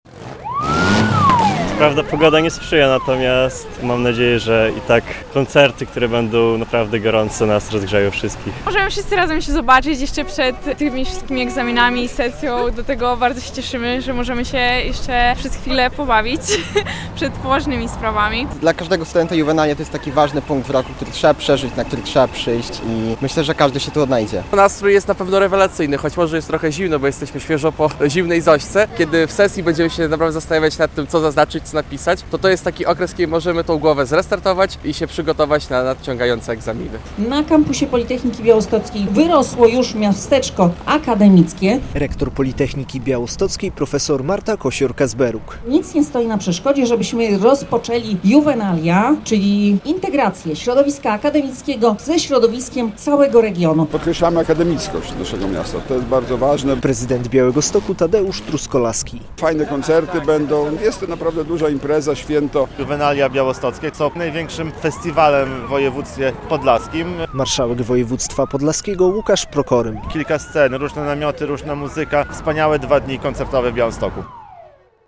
Parada studentów - relacja